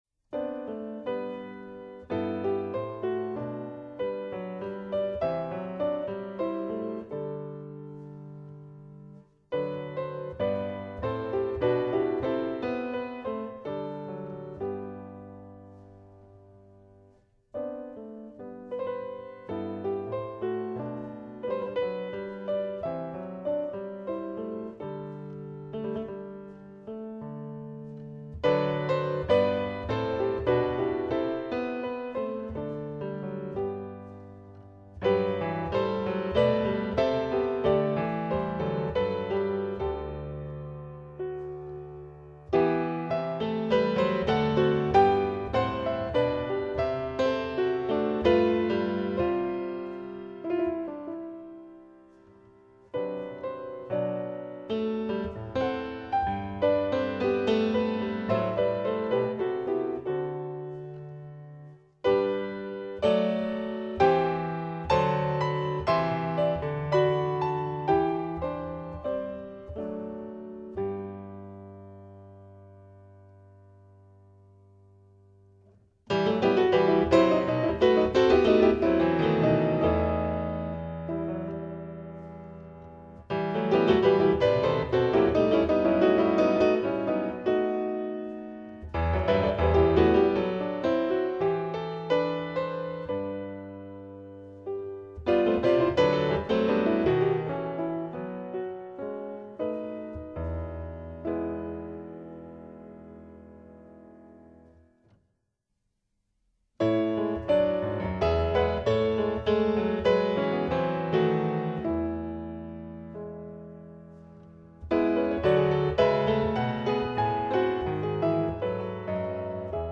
mezzo di esecuzione: pianoforte